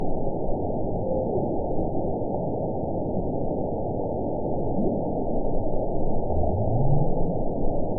event 910631 date 01/23/22 time 18:38:37 GMT (3 years, 10 months ago) score 9.45 location TSS-AB06 detected by nrw target species NRW annotations +NRW Spectrogram: Frequency (kHz) vs. Time (s) audio not available .wav